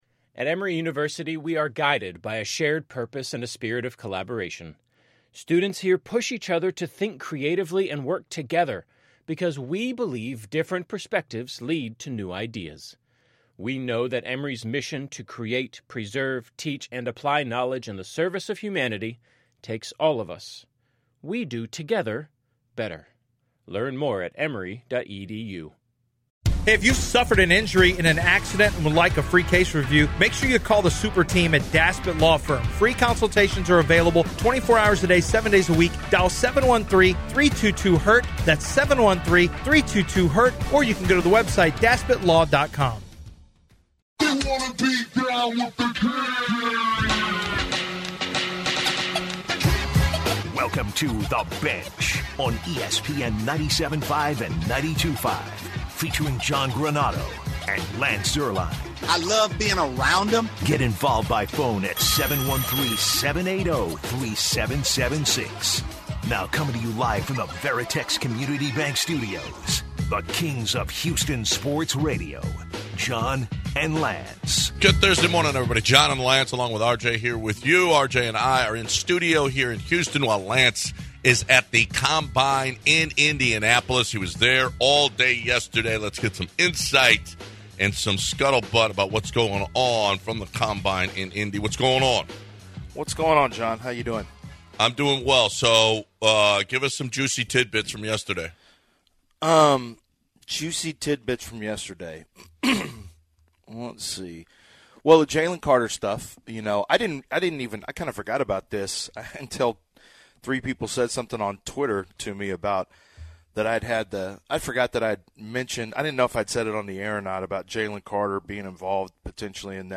In our opening hour, we hear from DeMeco Ryans from the combine and gather his philosophies for the NFL Draft and this upcoming season.
In addition, how does the Jalen Carter story shake up the NFL Draft moving forward? The guys speak on all that and more on The Bench.